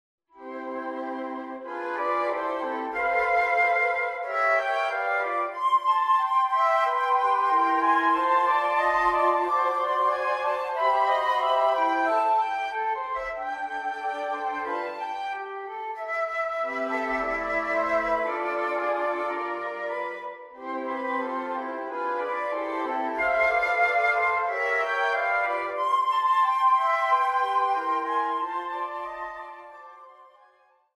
show tune